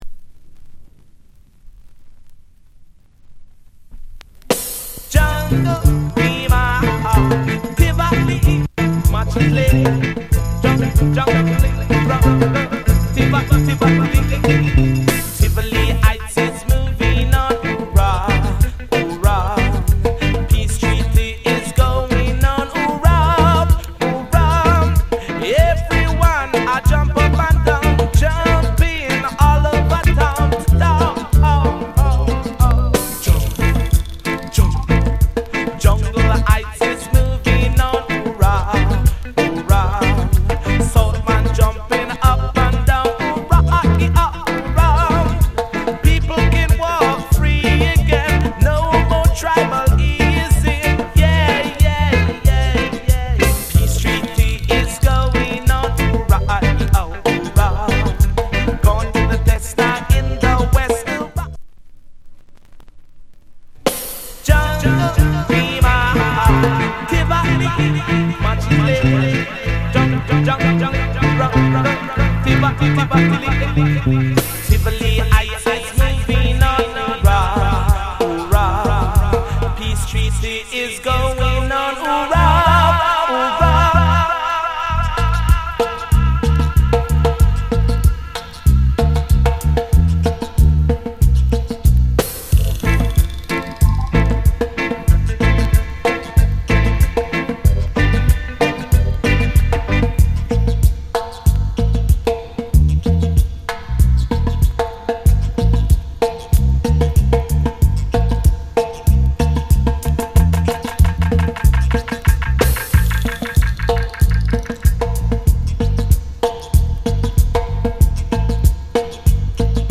Genre Roots Rock / Male Vocal